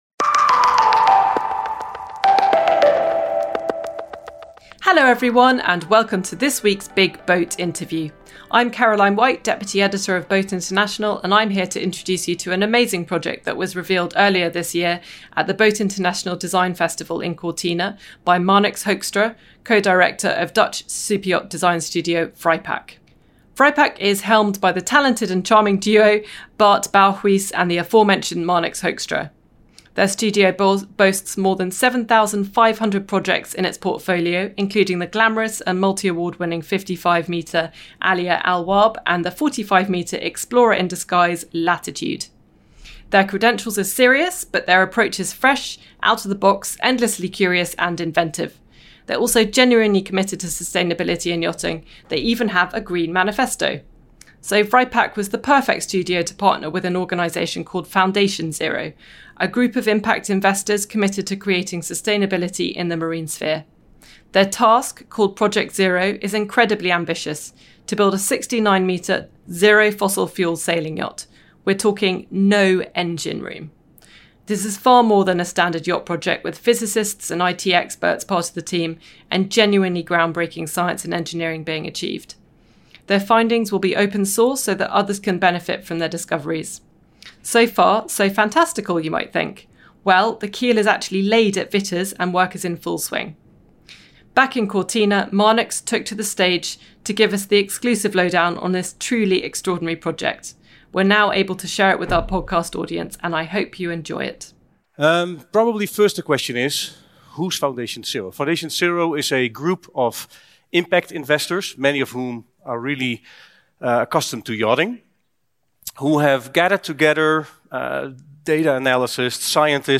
We’re now able to share this exclusive talk with you.